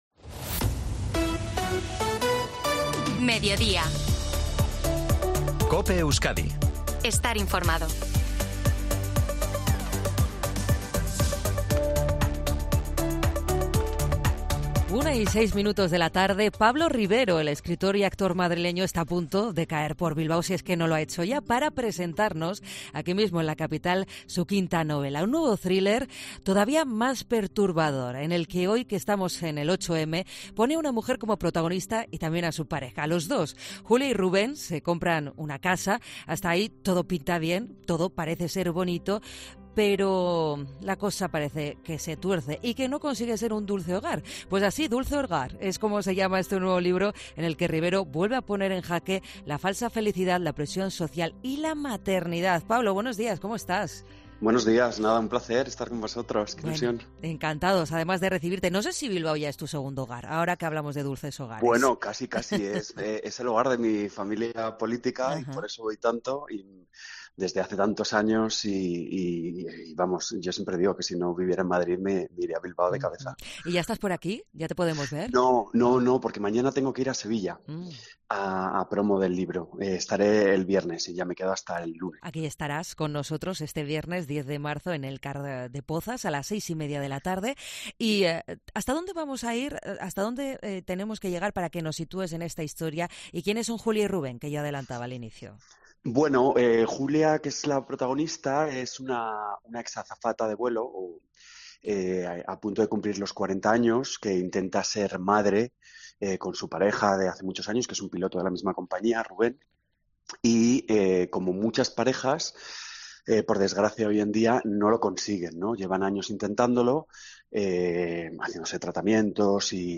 El escritor madrileño presenta en COPE Euskadi su quinta novela, 'Dulce Hogar'